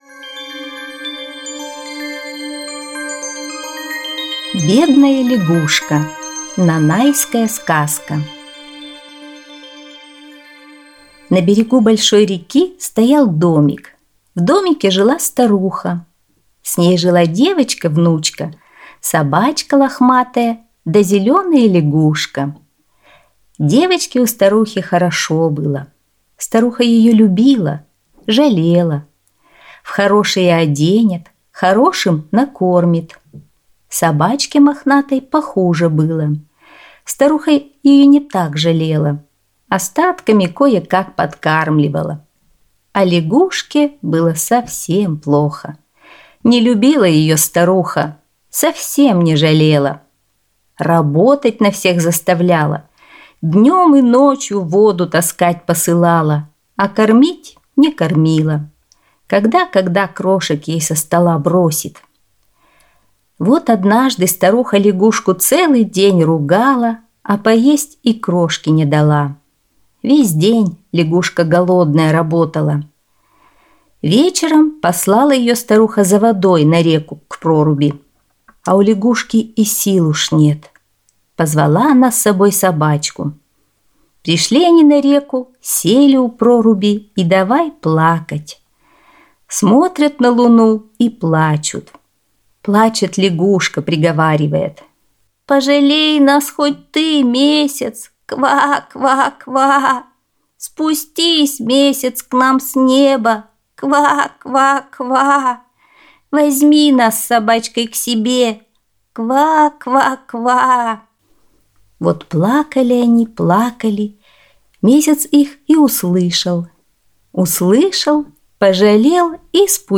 Аудиосказка «Бедная лягушка»